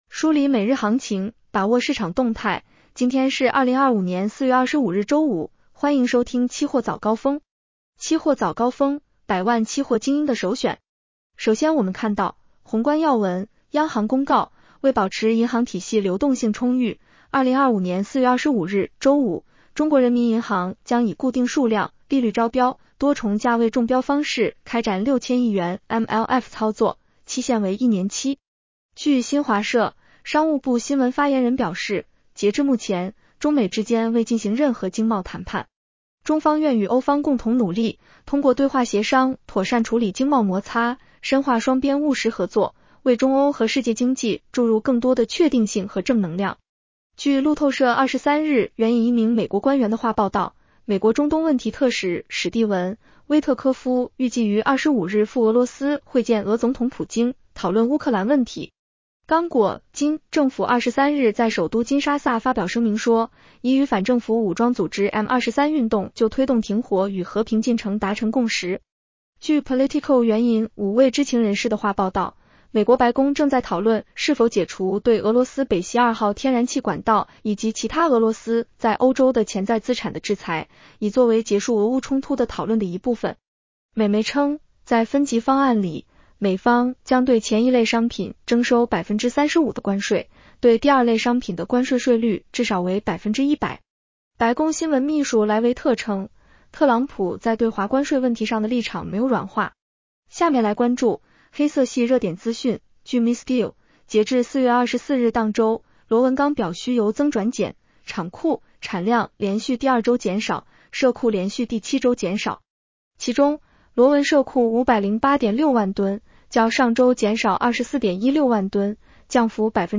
期货早高峰-音频版